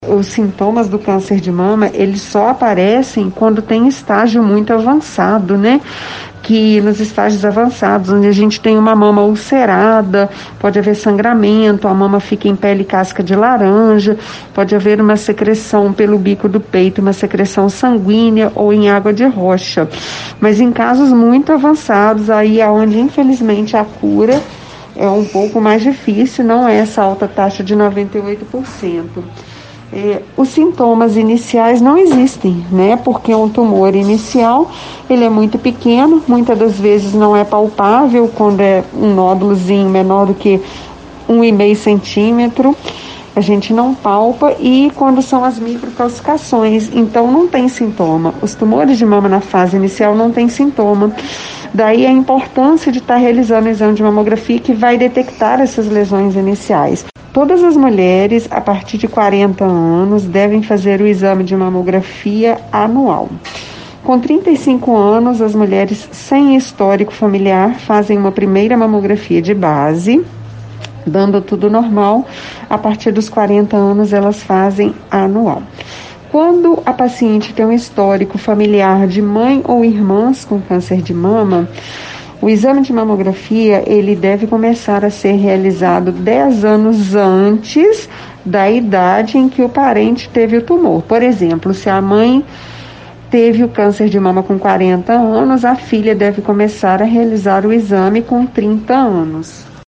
A médica faz um alerta sobre os sintomas e ressalta que o exame é destinado a mulheres com idades a partir dos 40 anos.